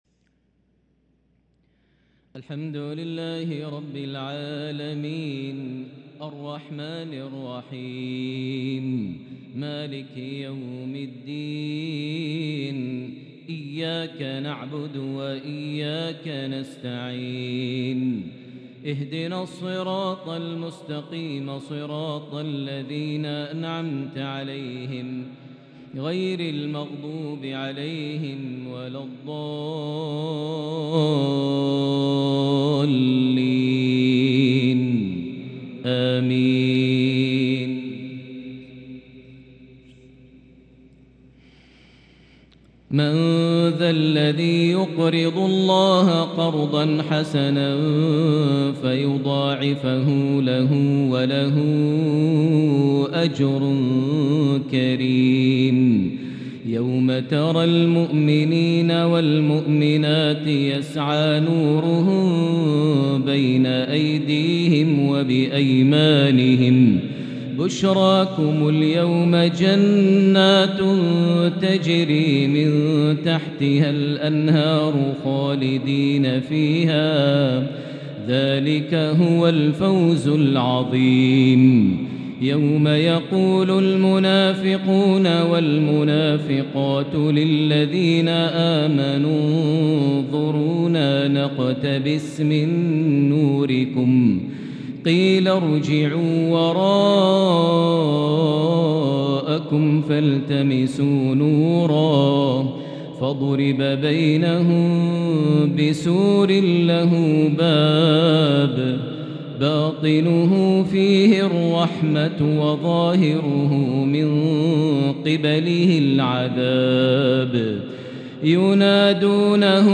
تلاوة روحانية خاشعة من سورة الحديد {11-21} | عشاء الأحد 8-2-1444هـ > 1444 هـ > الفروض - تلاوات ماهر المعيقلي